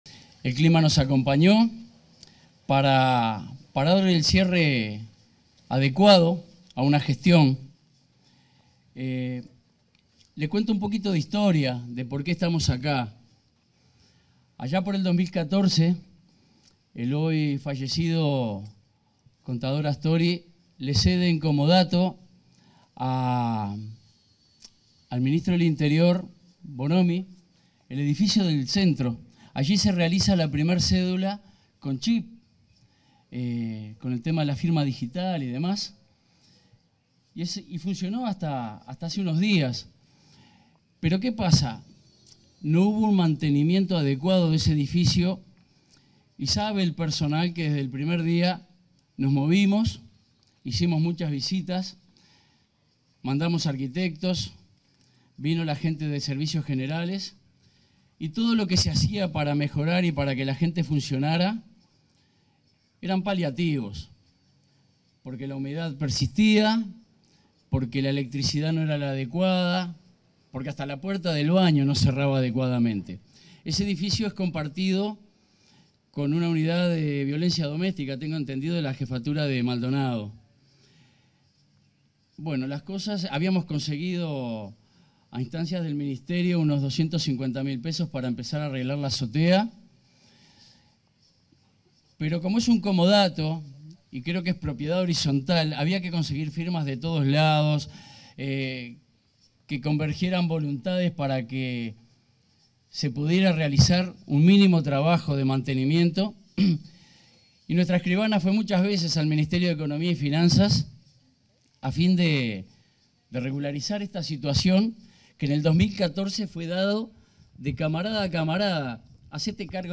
Palabras del director nacional de Identificación Civil, José Luis Rondan
Palabras del director nacional de Identificación Civil, José Luis Rondan 13/02/2025 Compartir Facebook X Copiar enlace WhatsApp LinkedIn En el marco de la reinauguración de la oficina de la Dirección Nacional de Identificación Civil, este 13 de febrero, se expresó el titular de esa dependencia, José Luis Rondan.